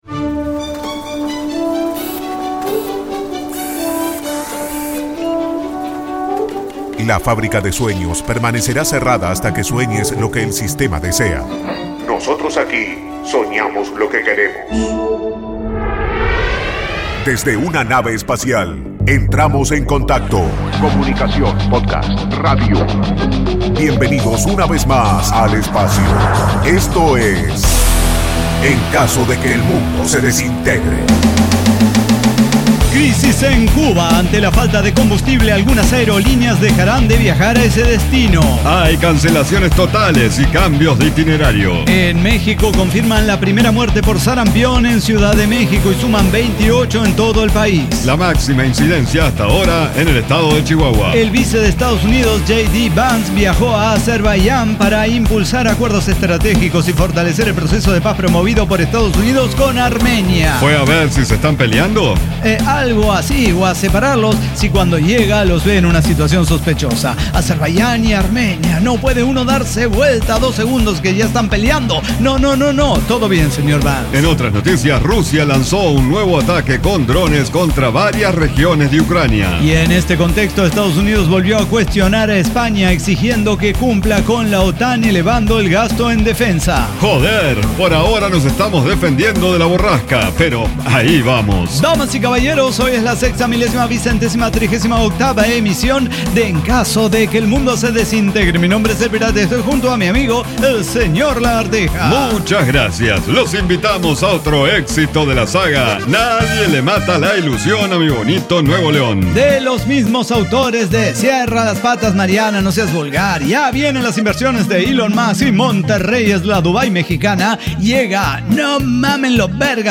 NO AI: ECDQEMSD Podcast no utiliza ninguna inteligencia artificial de manera directa para su realización. Diseño, guionado, música, edición y voces son de nuestra completa intervención humana.